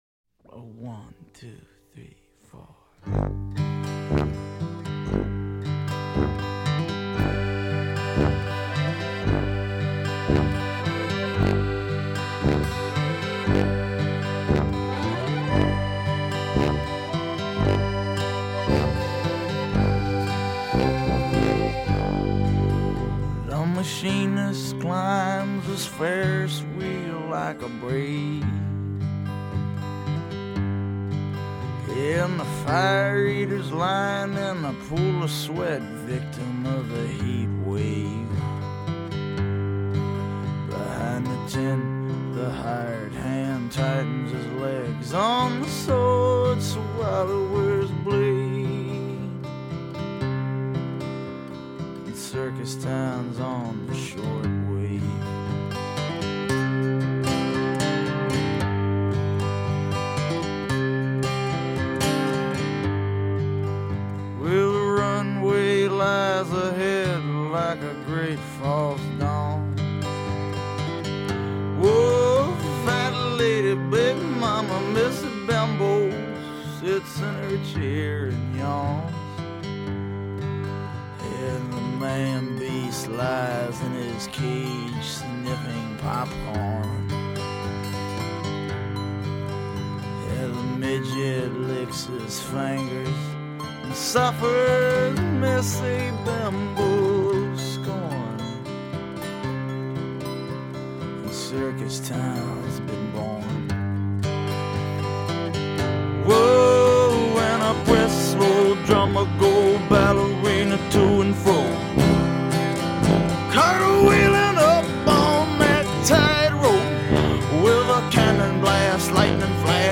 I think it was a mis-step to open the song with a tuba